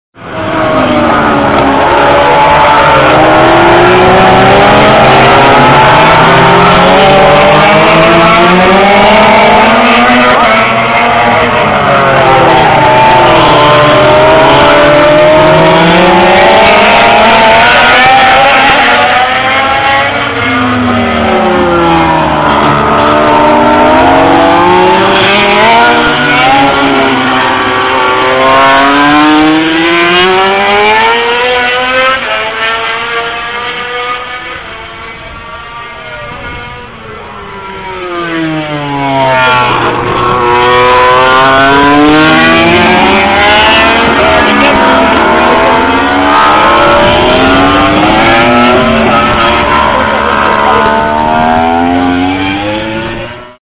J'en profite pour faire des prises de sons afin d'enregistrer ce bruit rageur que dégagent les MotoGP.
(Lorsqu'on entend le coup de trompe, c'est qu'il s'agit soit de